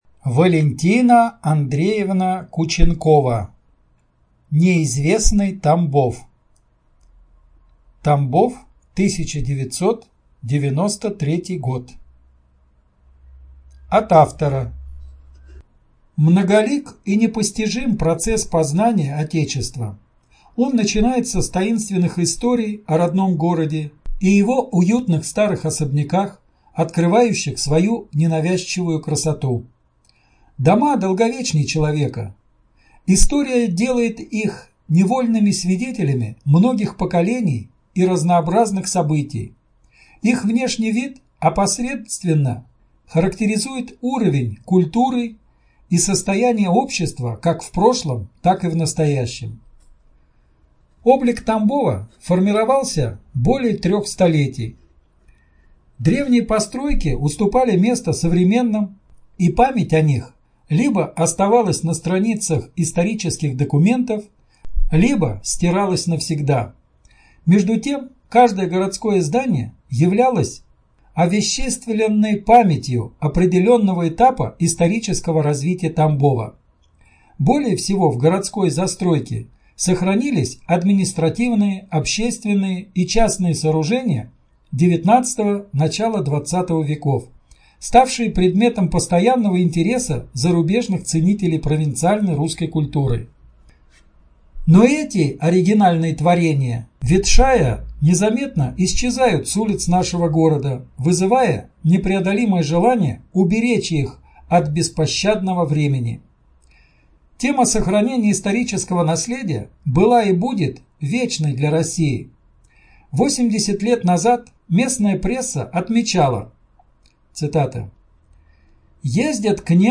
Студия звукозаписиТамбовская областная библиотека имени А.С. Пушкина